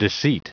Prononciation du mot deceit en anglais (fichier audio)